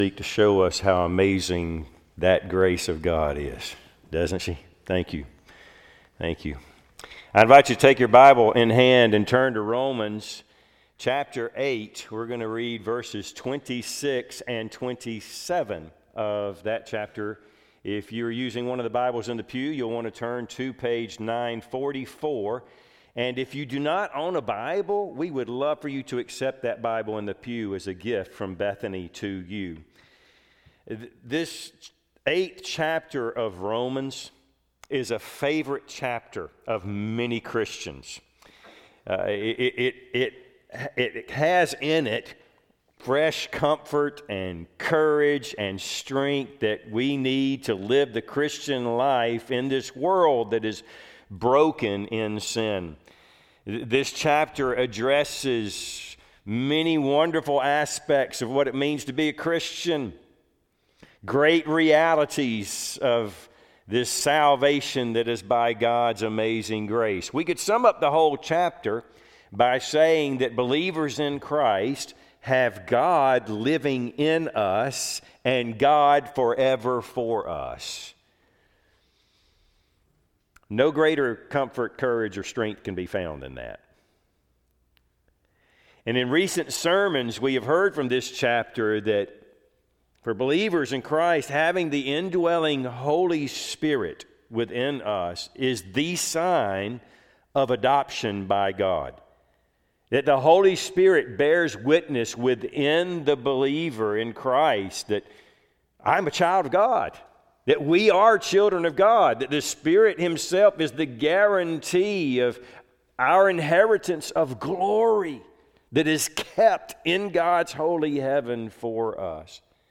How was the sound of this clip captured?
Service Type: Sunday AM Topics: Holy Spirit , Prayer , santification « Do You Know Who John Knew?